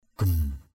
/ɡ͡ɣum/ (d.) mối thù. feud. palao gum p_l< g~’ gieo mối thù. bayar gum byR g~’ trả thù.